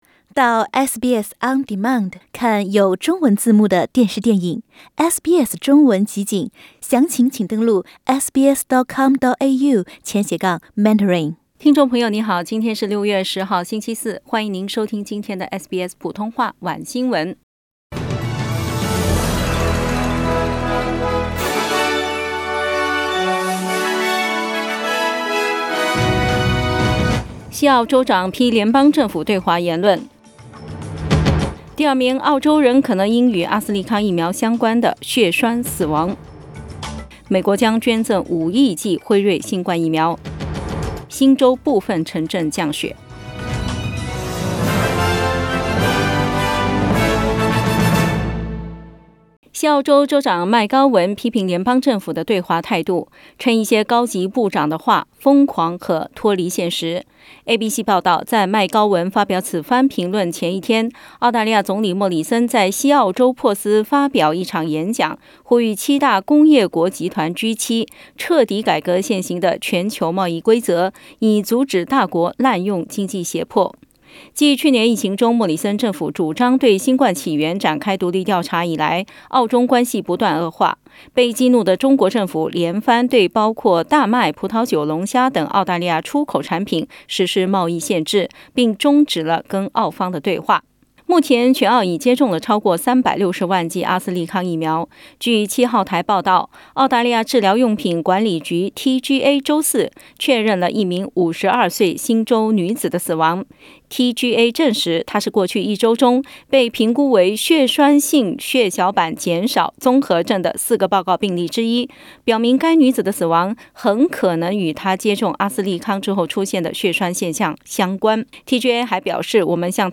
SBS晚新闻（6月10日）